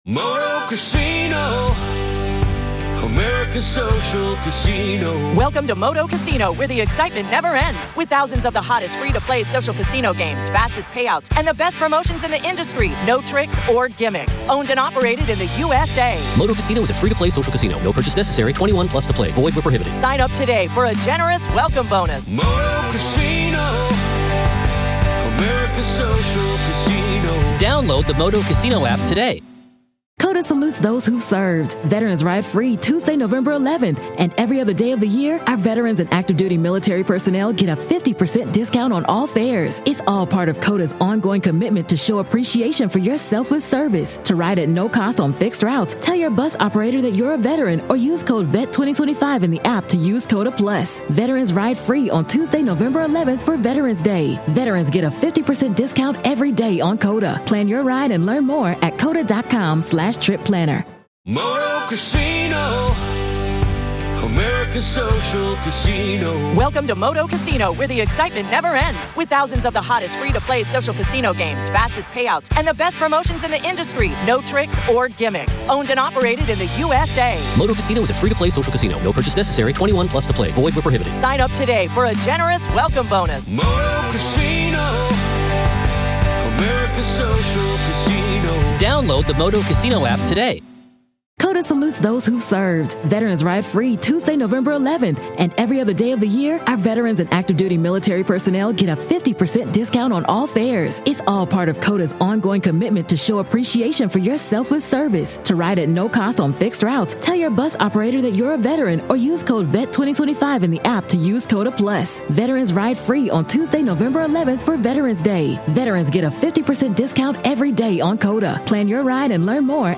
Each day’s proceedings bring new testimony, evidence, and revelations about what happened inside Richneck Elementary School on January 6, 2023 — and the administrative failures that followed. You’ll hear unfiltered courtroom audio, direct from the trial
Hidden Killers brings you the voices, the arguments, and the raw sound of justice in progress — as a jury decides whether silence and inaction inside a public school can rise to the level of legal accountability.